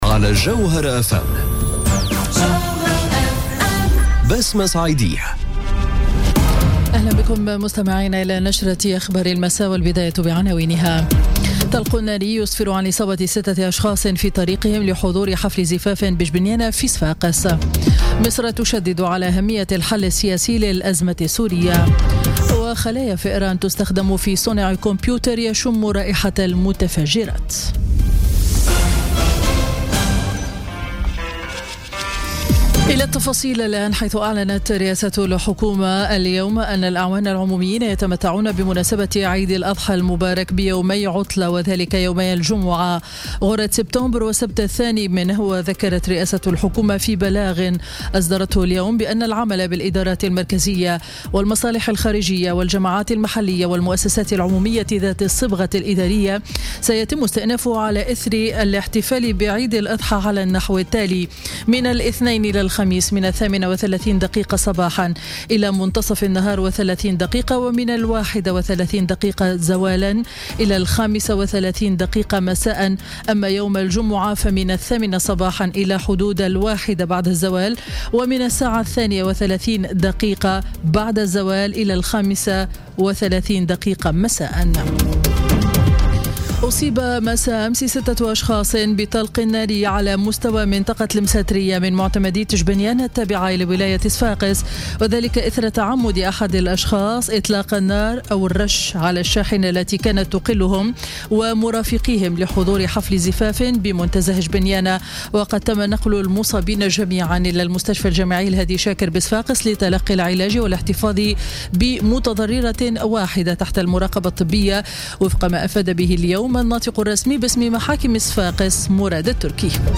نشرة أخبار السابعة مساء ليوم الاثنين 28 أوت 2017